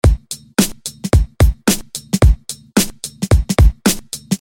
描述：嘻哈,节拍,鼓声,鼓声循环
Tag: 110 bpm Hip Hop Loops Drum Loops 752.42 KB wav Key : Unknown